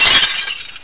glass2.wav